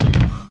sound_mecha_powerloader_step.ogg